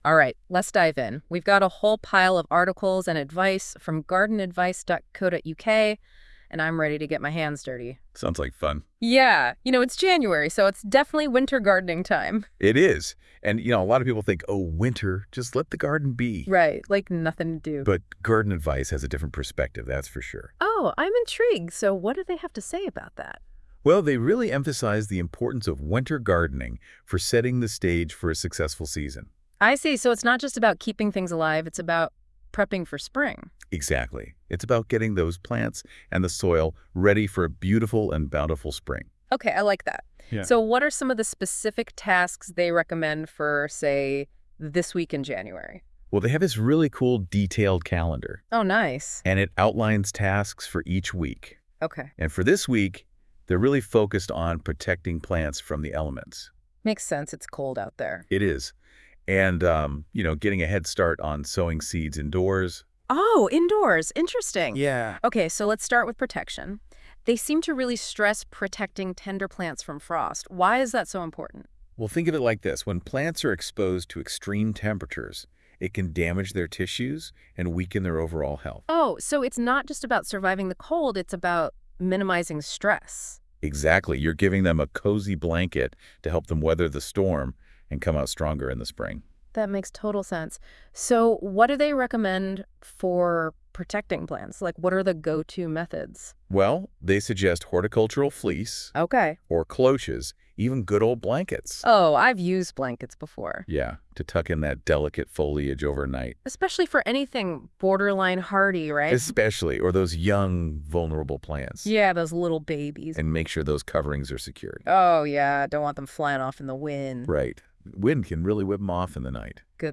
Click Here for Audio Google deep dive podcast review of the GardenAdvice Calendar 3rd week in January and GardenAdvice features as a downloadable .WAV file